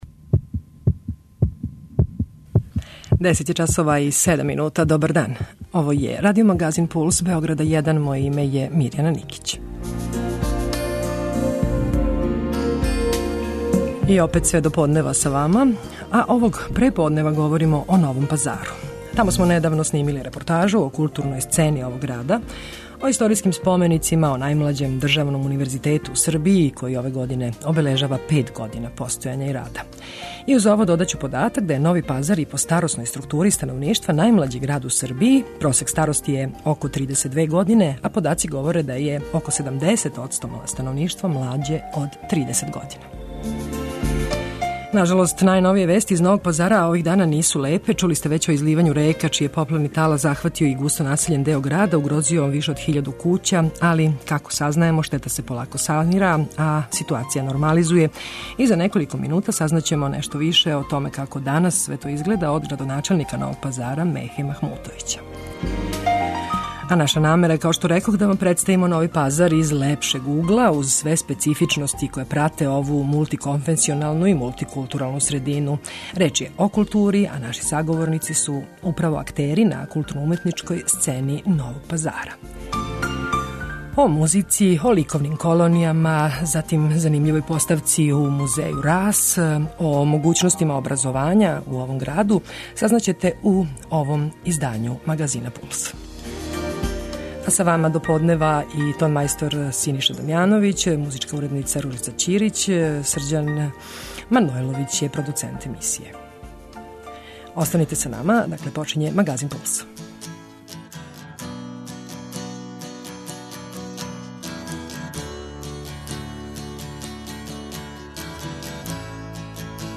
Током турнеје Радио Београда 1 били смо у Новом Пазару и забележили репортажу о културном животу овог града.
Саговорници су нам актери културне сцене Новог Пазара, млади људи, што није ништа чудно, с обзиром да је овај град - град младих, чак 49 одсто становништва чине млађи од 29 година.